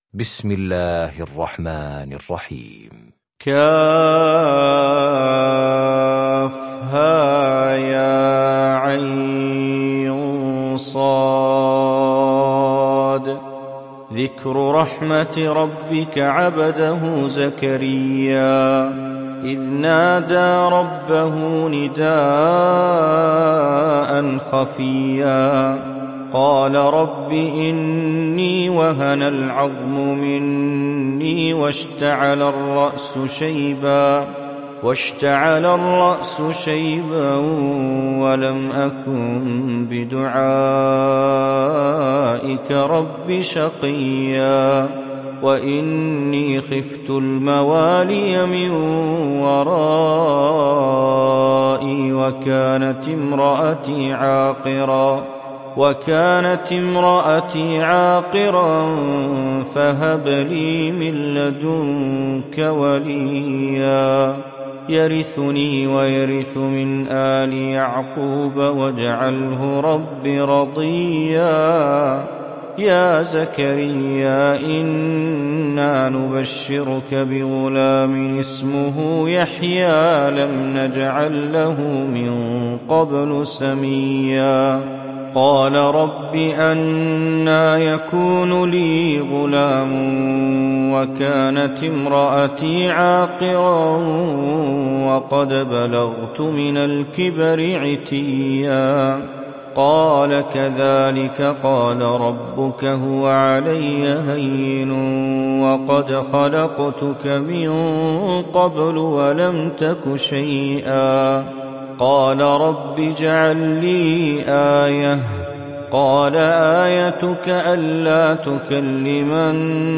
سورة مريم مكية عدد الآيات:98 مكتوبة بخط عثماني كبير واضح من المصحف الشريف مع التفسير والتلاوة بصوت مشاهير القراء من موقع القرآن الكريم إسلام أون لاين